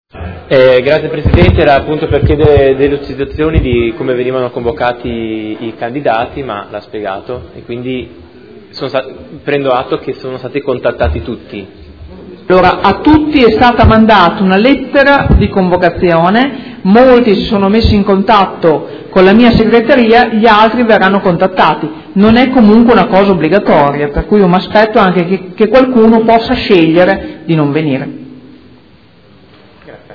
Seduta del 21/09/2015. Richiesta chiarimenti del consigliere Chincarini sulle audizioni dei candidati al cda della Fondazione cassa di Risparmio di Modena